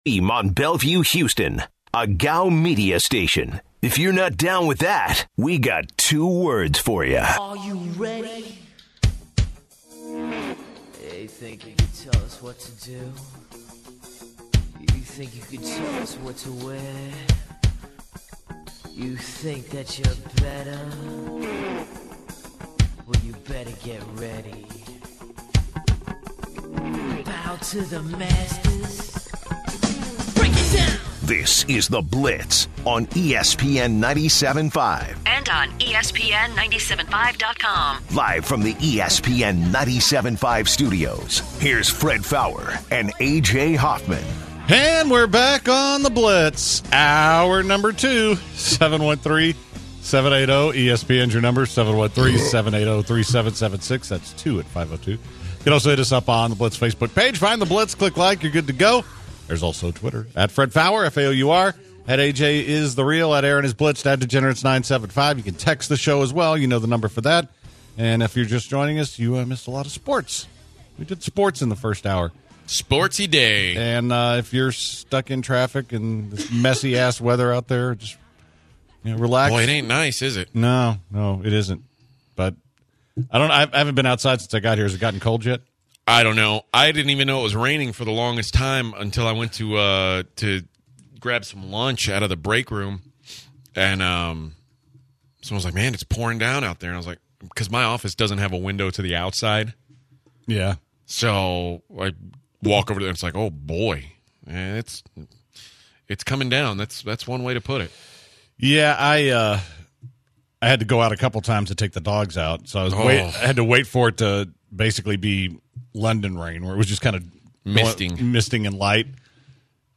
The guys then continue to talk about the browns and they take calls and discuss different NFL topics.